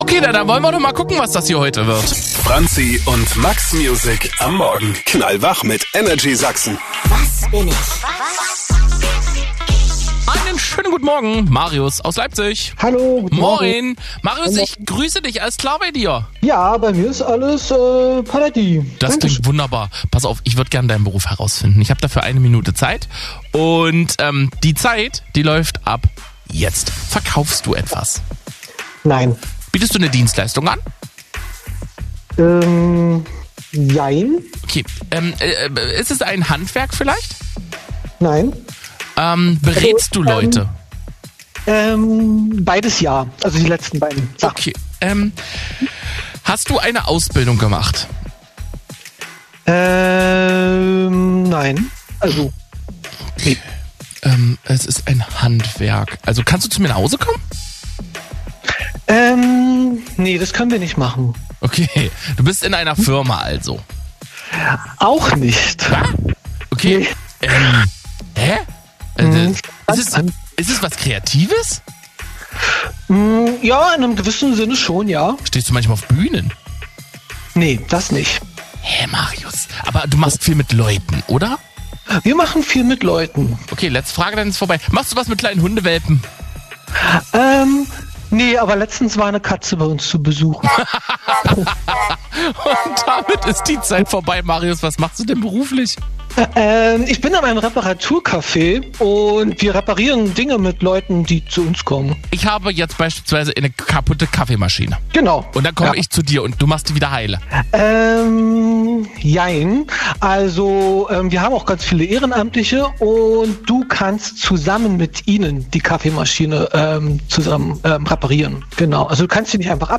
die Moderator*innen haben versucht seinen Beruf zu erraten.